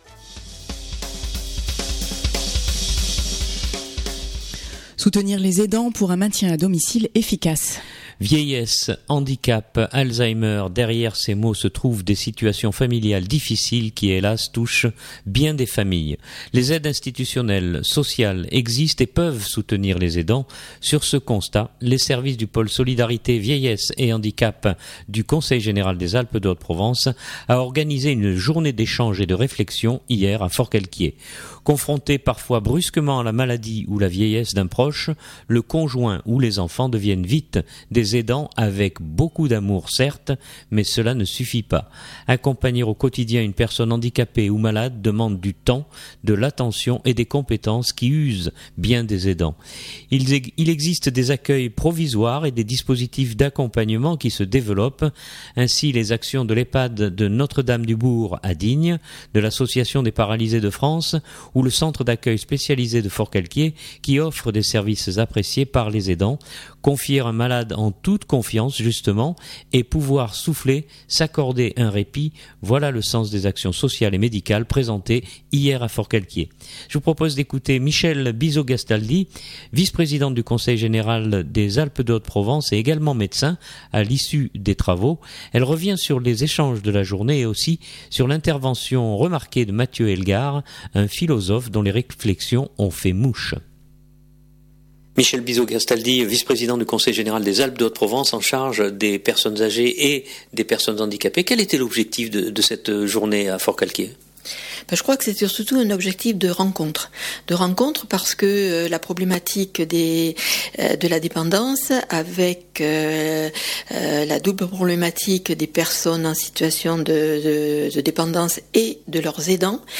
Je vous propose d’écouter Michèle Bizot-Gastaldi, vice-présidente du Conseil Général des Alpes de Haute-Provence à l’issue des travaux.